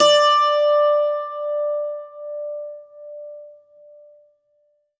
STRINGED INSTRUMENTS
52-str12-bouz-d4.wav